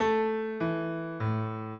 minuet7-10.wav